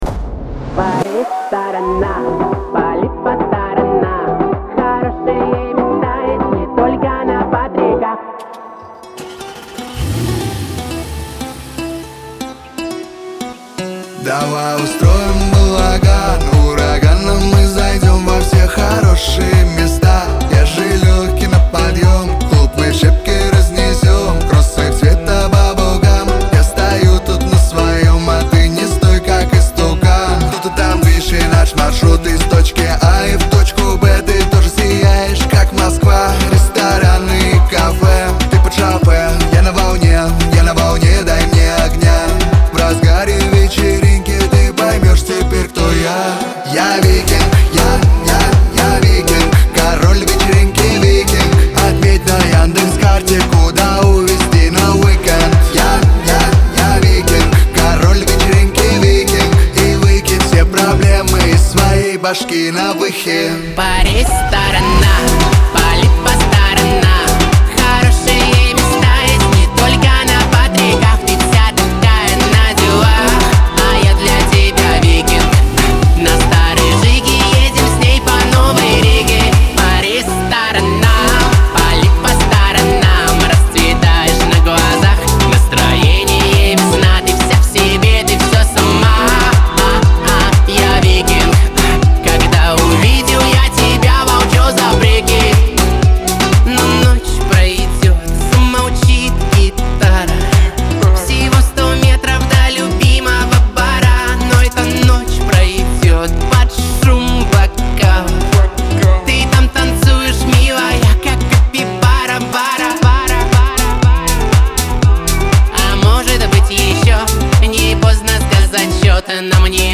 Главная ➣ Жанры ➣ Эстрада. 2025.
дуэт , pop
Веселая музыка